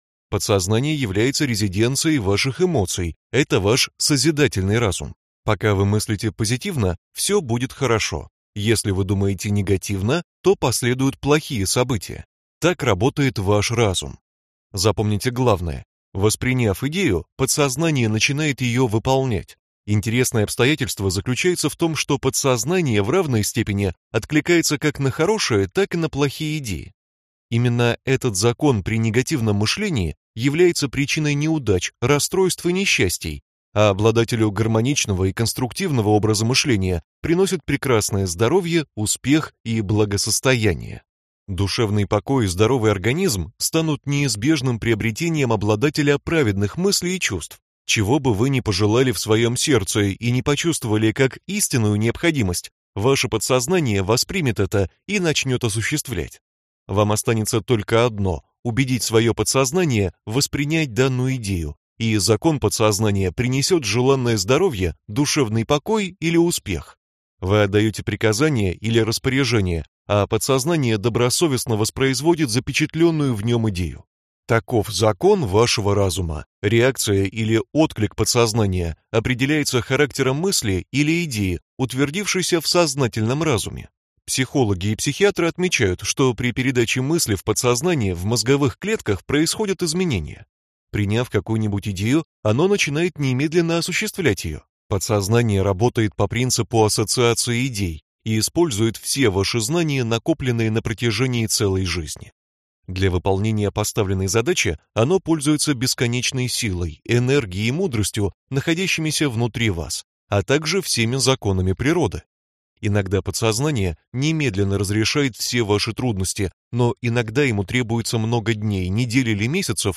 Аудиокнига Сила вашего подсознания | Библиотека аудиокниг